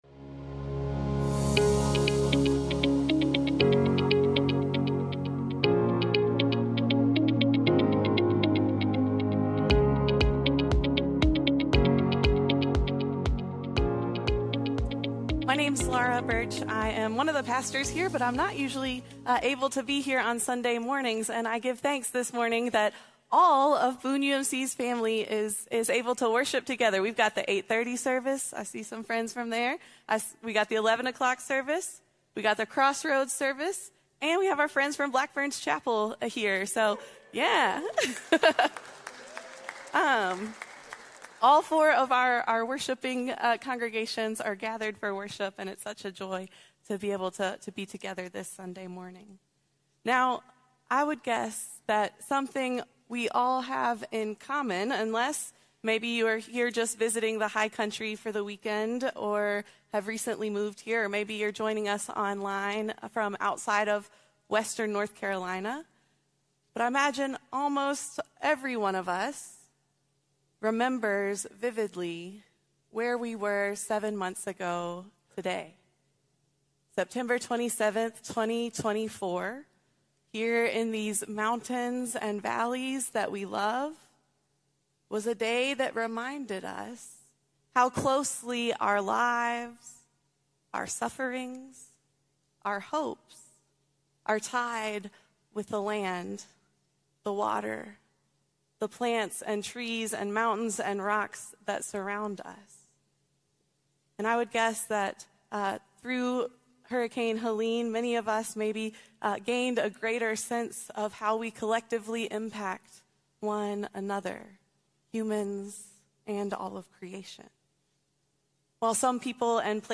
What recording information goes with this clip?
(April 27, 2025) “All Creation Set Free”-Combined Healing Service